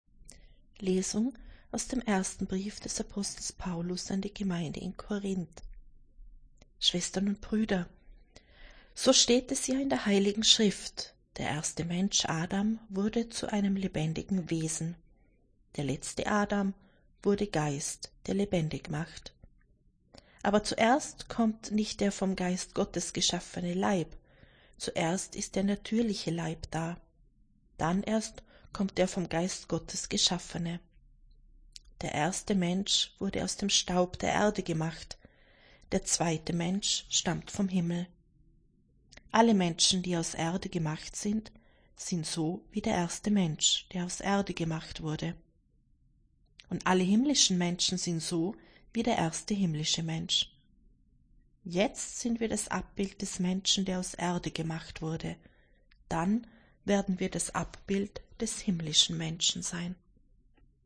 Wenn Sie den Text der 2. Lesung aus dem Brief des Apostels Paulus an die Gemeinde in Korínth anhören möchten: